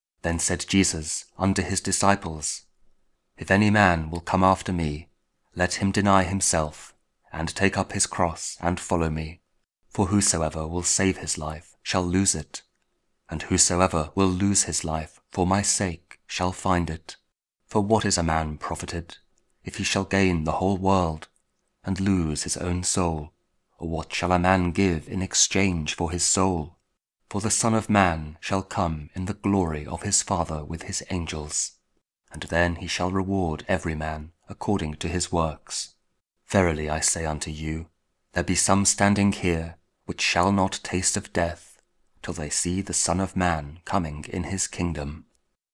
Matthew 16: 24-28 – Week 18 Ordinary Time, Friday (King James Audio Bible KJV, Spoken Word)